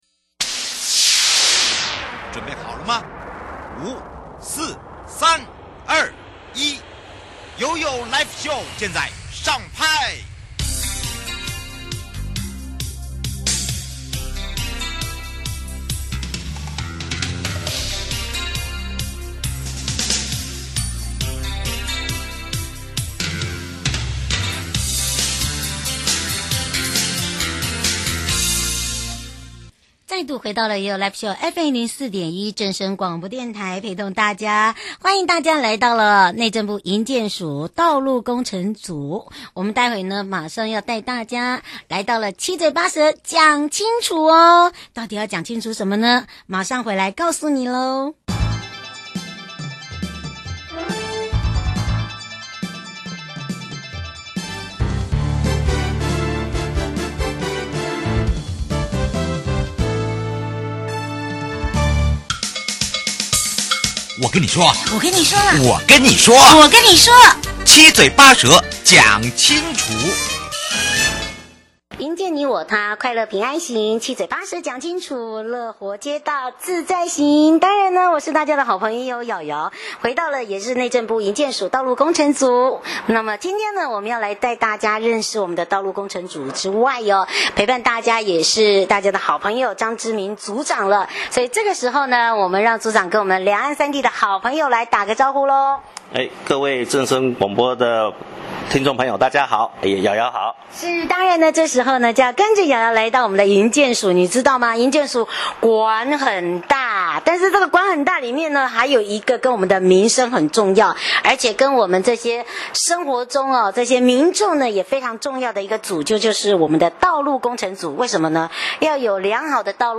受訪者： 營建你我他 快樂平安行-七嘴八舌講清楚 樂活街道自在同行- 來到營建署才知道營建署管很大，裡面還有一